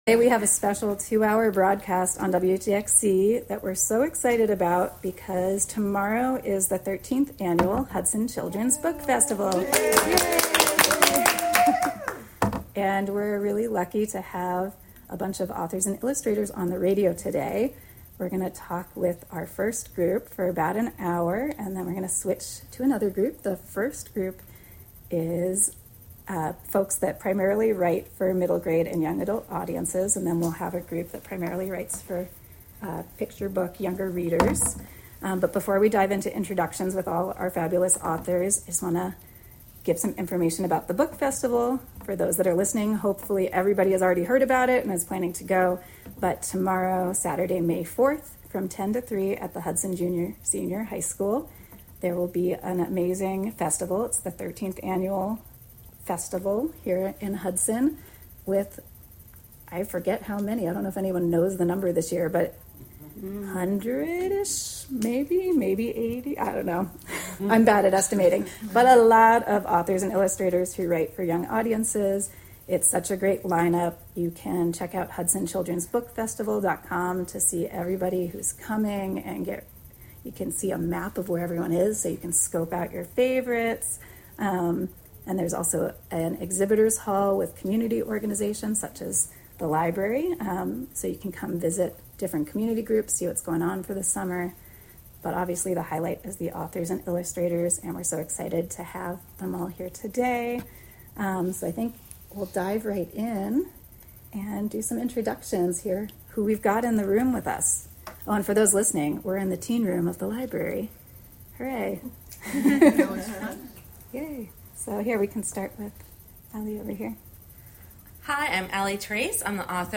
Live from the Hudson Area Library